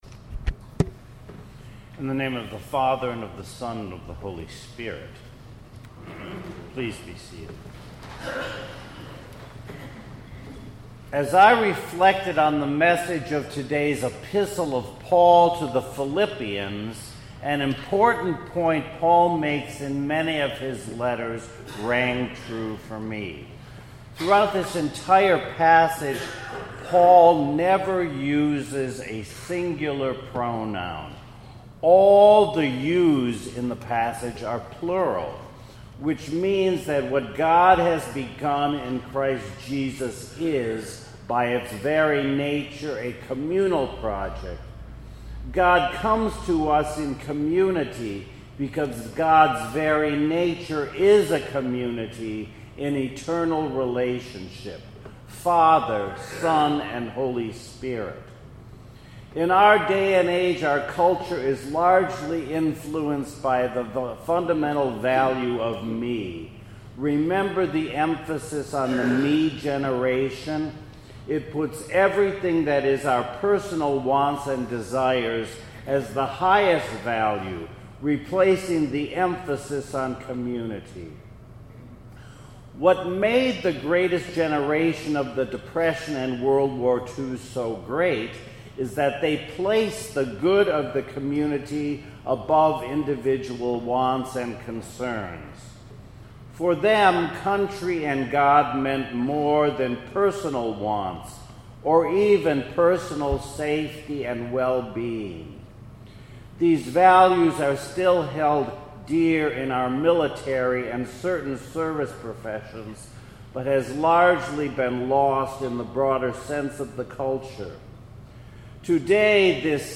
2018 Sunday Sermon